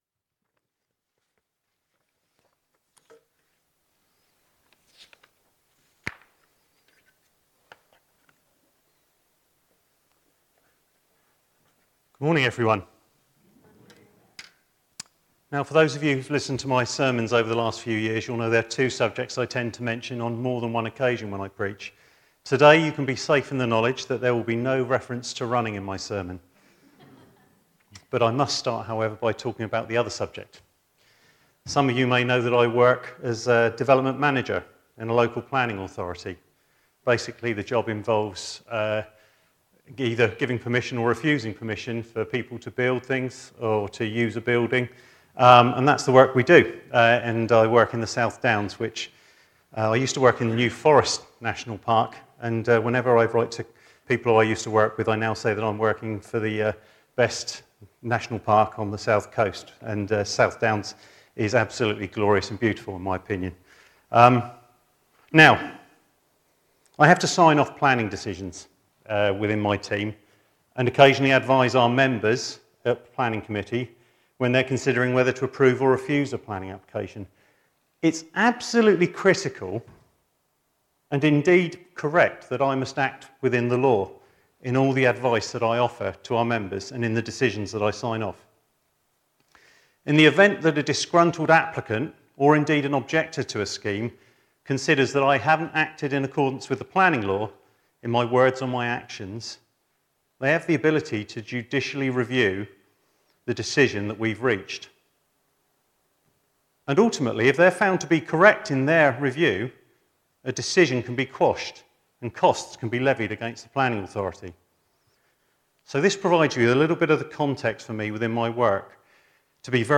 A message from the series "God: in Person."